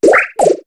Cri de Doudouvet dans Pokémon HOME.